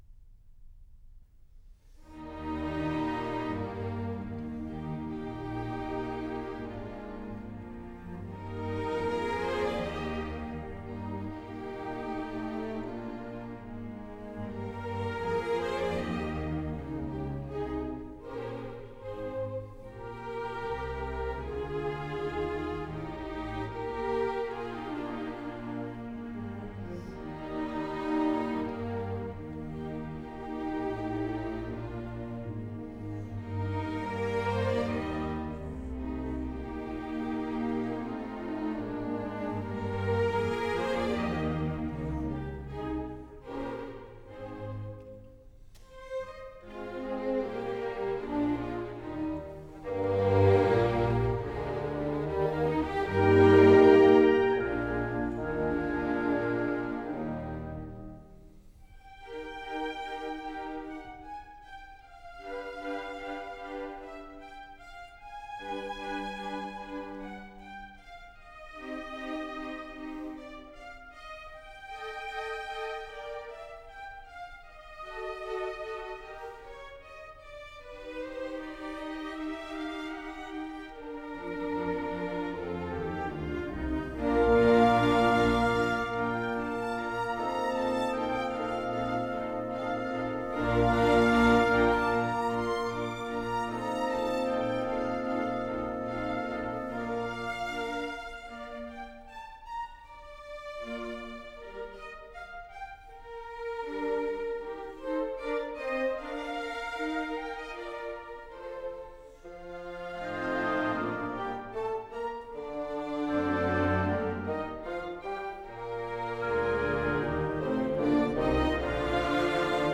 » nhac-khong-loi